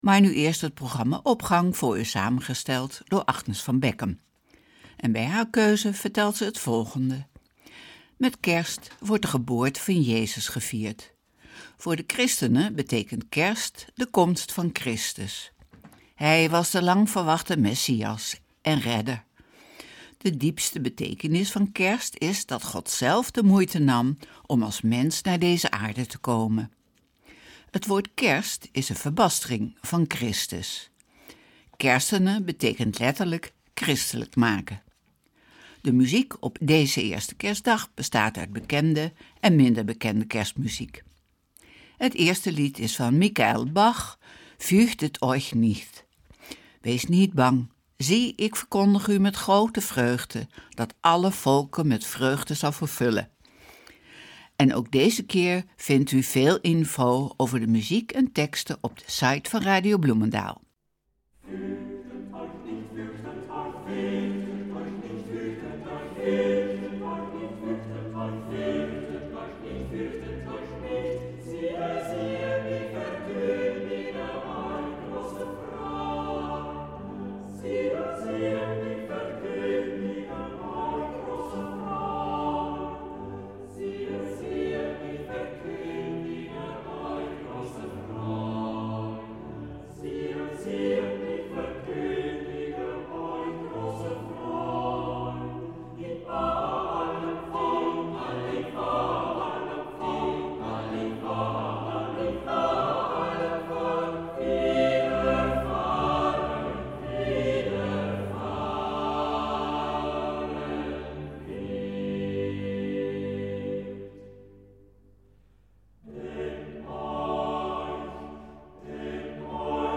Opening van deze Kerstdienst met muziek, rechtstreeks vanuit onze studio.